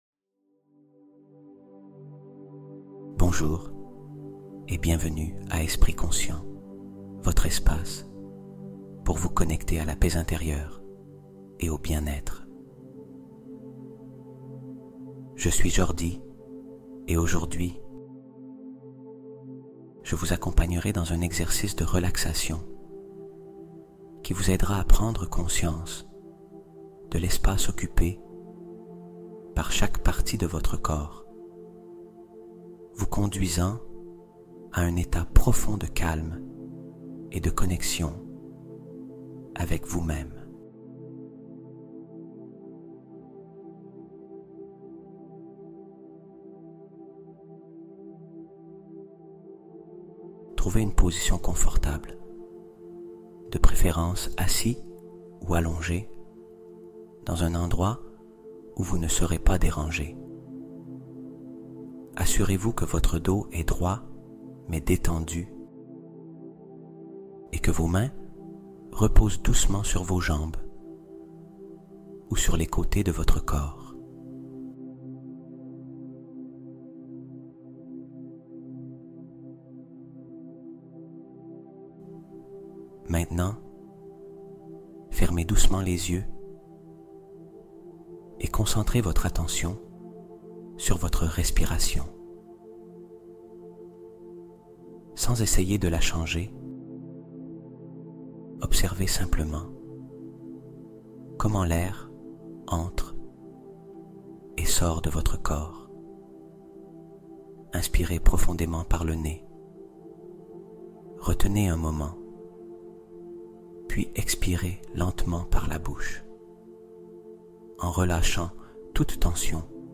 Soutenir la guérison corporelle par une visualisation guidée apaisante